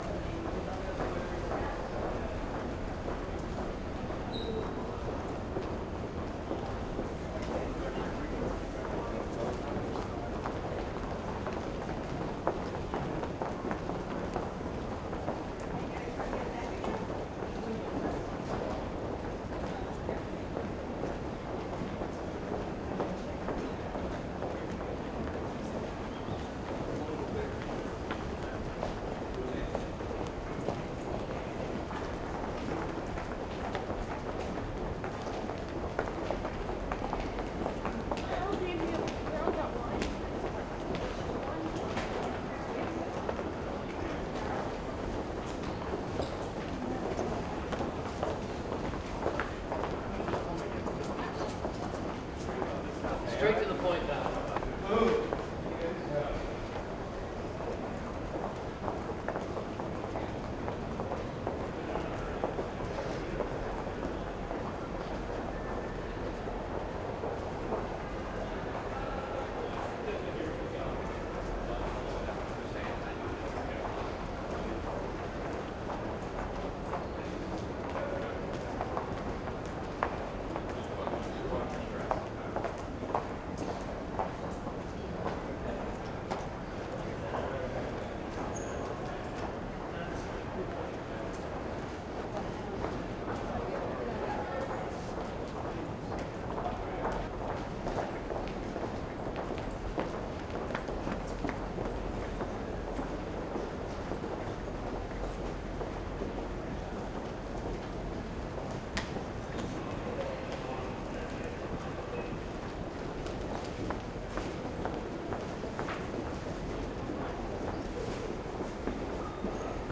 GrandCentral.L.wav